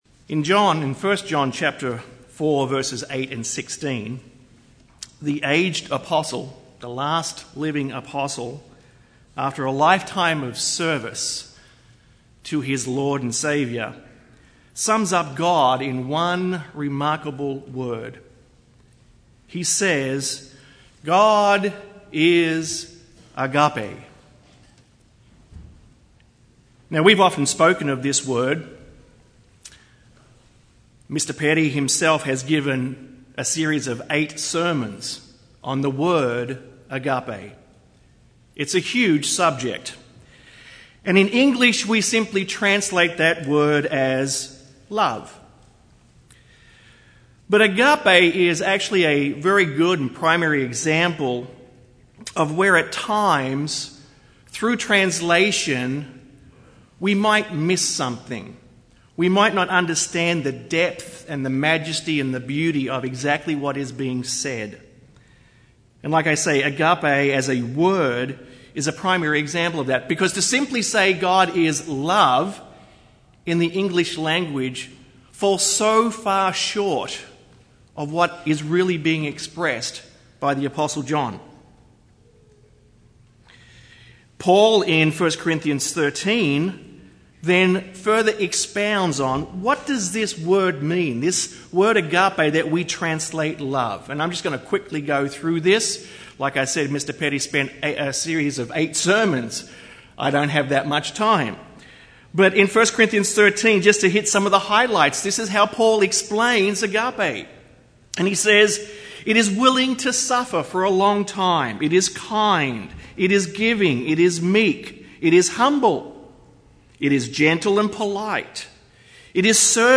This sermon was given at the New Braunfels, Texas 2013 Feast site.